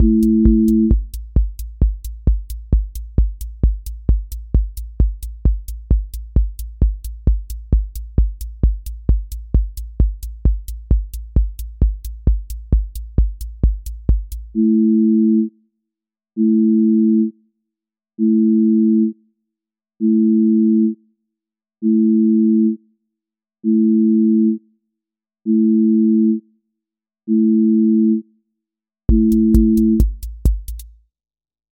trance euphoria
trance ascent with breakdown and drop
• voice_kick_808
• voice_hat_rimshot
• voice_sub_pulse
• fx_space_haze_light
• tone_brittle_edge